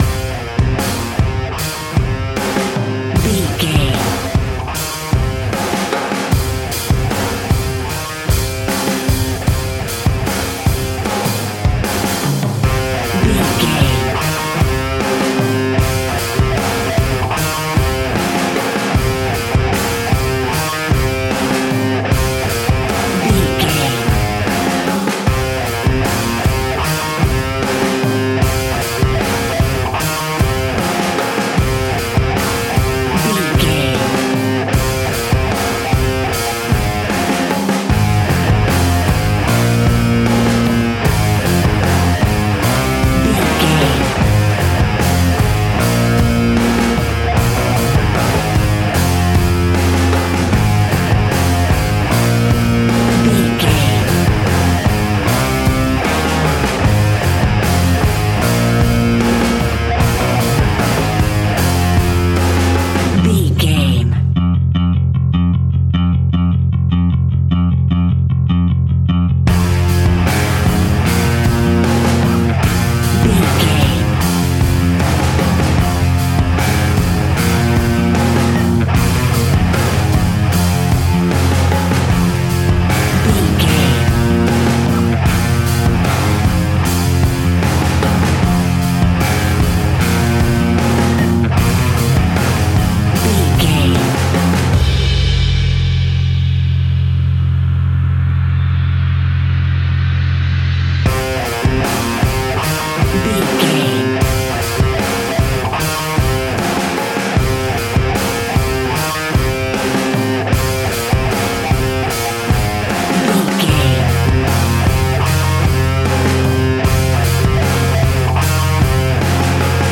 Ionian/Major
F♯
hard rock
heavy rock
distortion